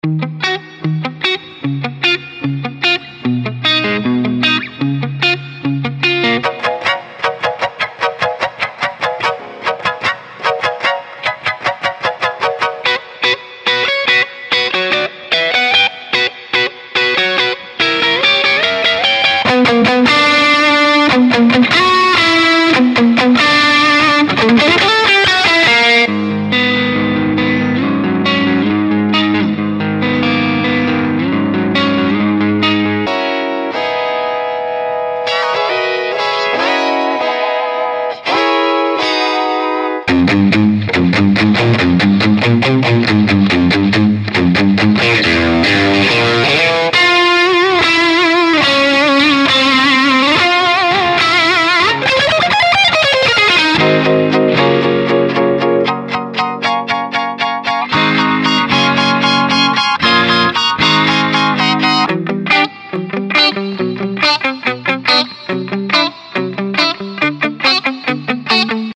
42 Unique Guitar Riffs: Dive into a versatile selection of guitar loops, ranging from dark, moody melodies to aggressive, high-energy riffs.
Expect pristine sound and exceptional clarity to make your tracks stand out.